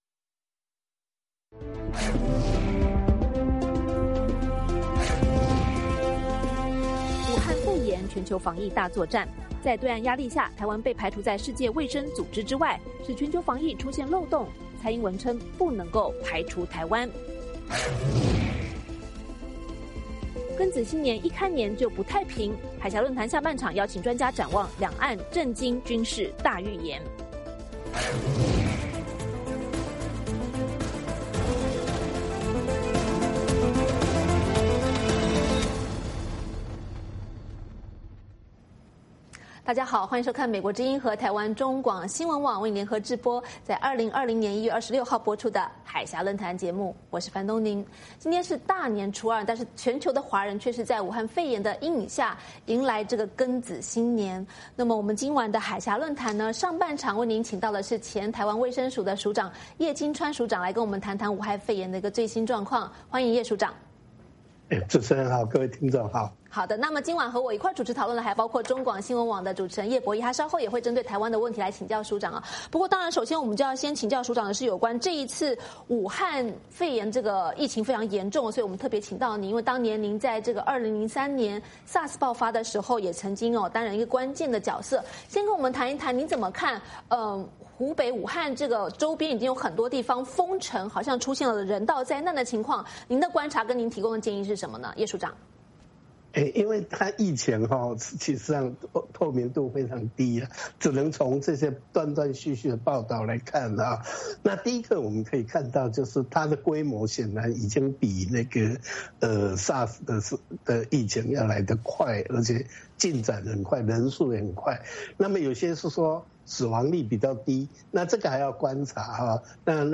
《海峡论谈》专访2003年抗SARS英雄、前台湾卫生署署长叶金川，分析武汉封城以及中共官方拒绝外援的做法是否可能造成人道灾难?